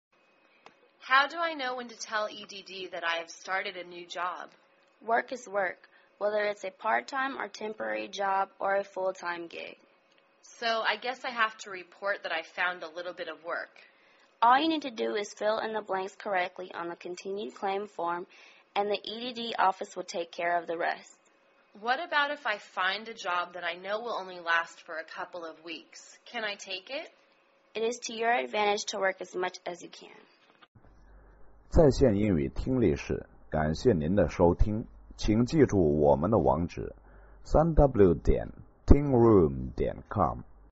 工作英语对话-Finding a Job(3) 听力文件下载—在线英语听力室